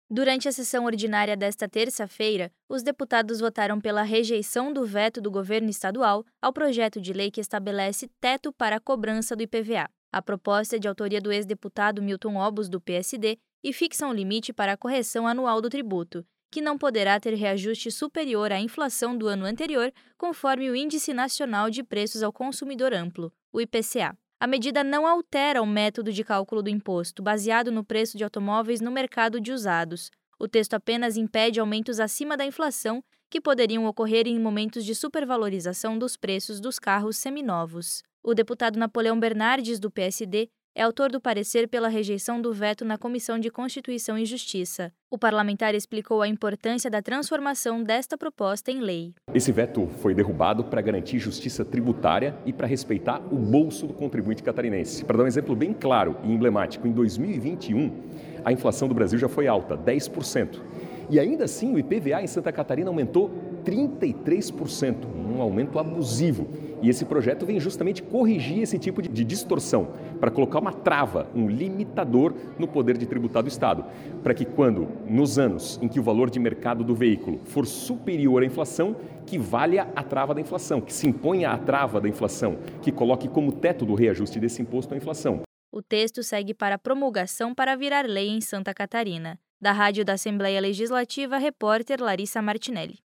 Entrevista com:
- deputado Napoleão Bernardes (PSD), autor do parecer pela rejeição do veto na CCJ.